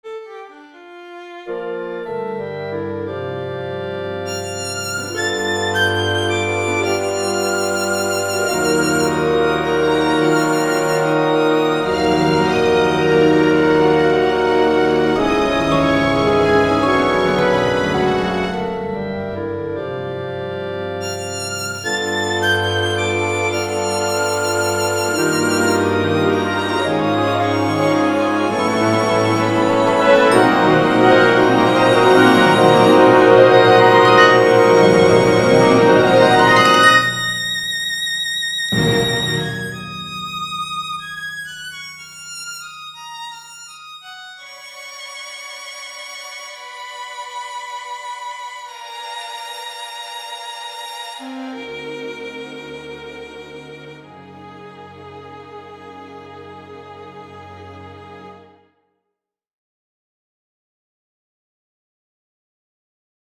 Here is something for violin and large orchestra that I am working on -- "Appassionato" (working title) for violin and large orchestra. Schmaltzy, I know, but I plan for this to be the development.
ViolinRhapsody_Orch_SlowPartAudio.mp3